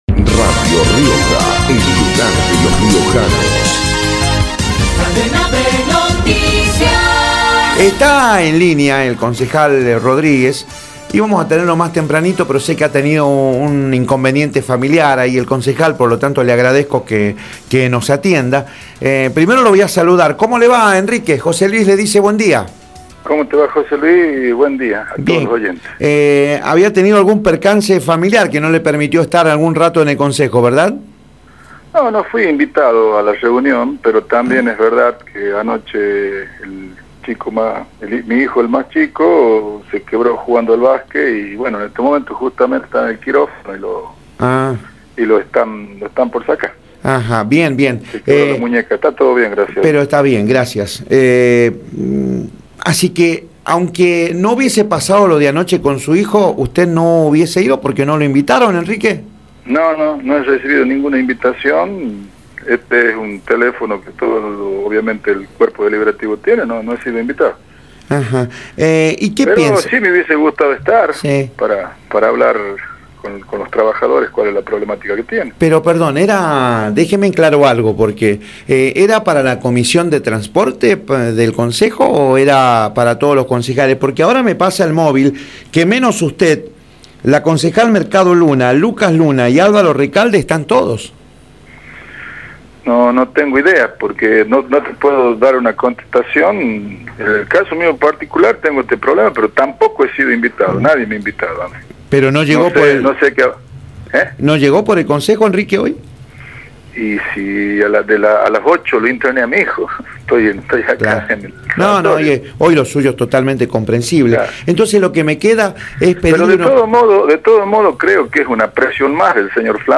Enrique Rodríguez, concejal, por Radio Rioja
enrique-rodrc3adguez-concejal-por-radio-rioja.mp3